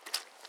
Water Walking 1_06.wav